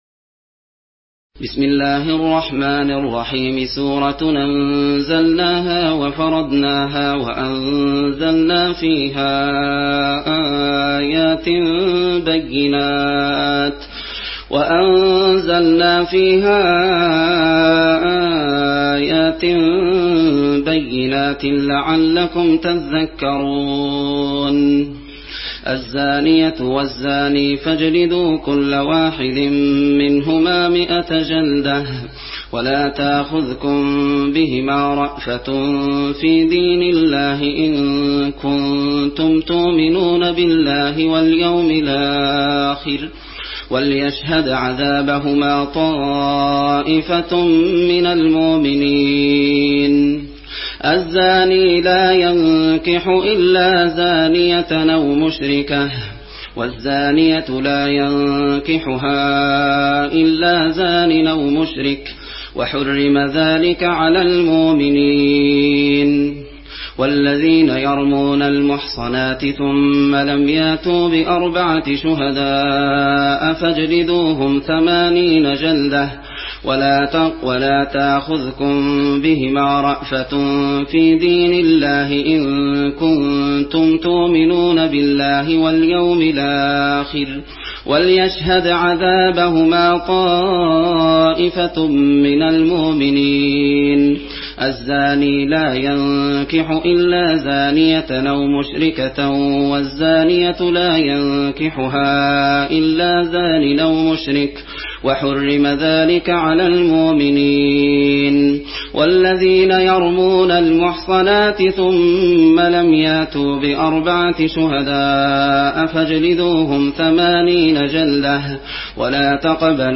ورش عن نافع